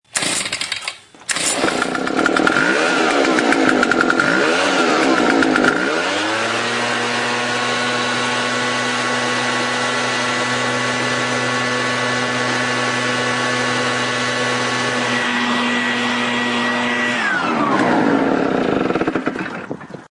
Download Leaf Blower sound effect for free.
Leaf Blower